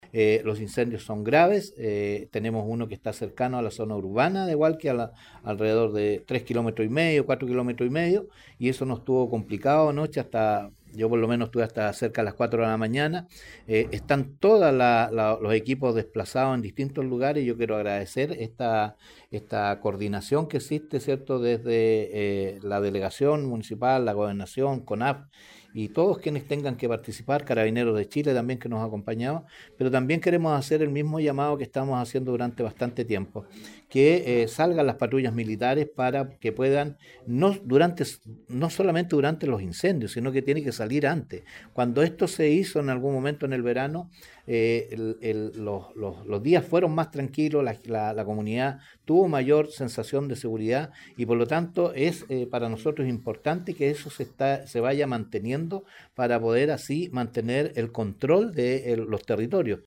Lo mismo hizo su par de Hualqui y presidente de la Asociación de Municipalidades, Ricardo Fuentes.